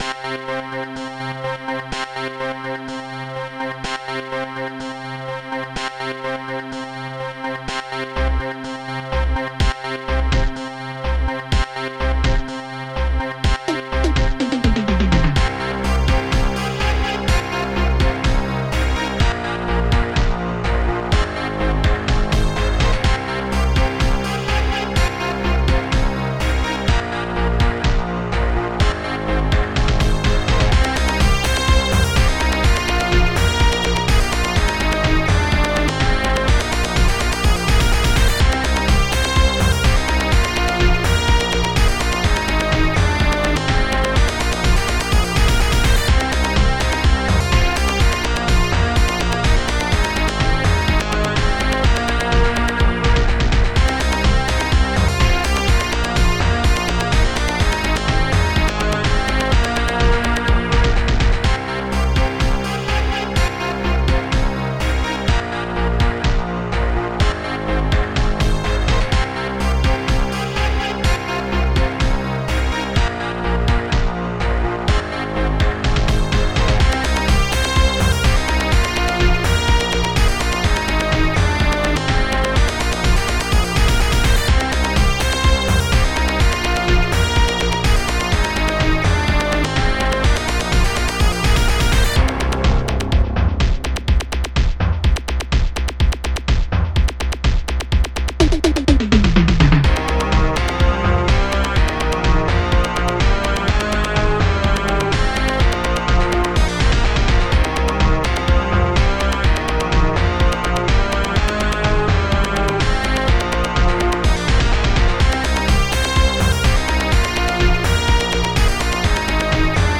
Instruments st-06:laser3 st-06:mixfx2 st-06:snare st-06:toma st-05:bass7 st-06:shagonyfx